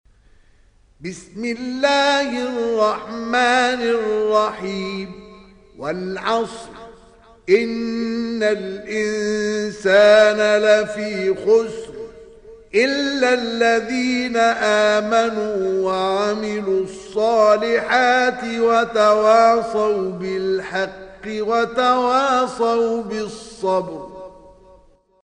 دانلود سوره العصر mp3 مصطفى إسماعيل روایت حفص از عاصم, قرآن را دانلود کنید و گوش کن mp3 ، لینک مستقیم کامل